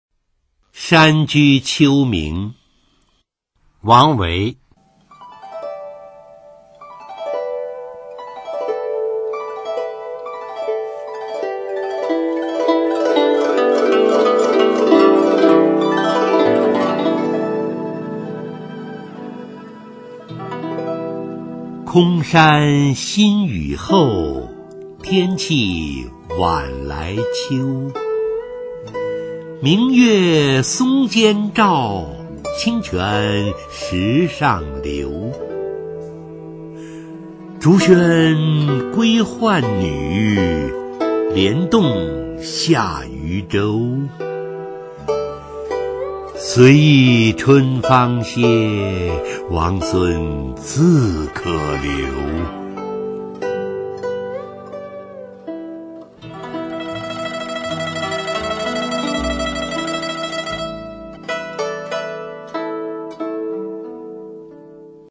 [隋唐诗词诵读]王维-山居秋暝 配乐诗朗诵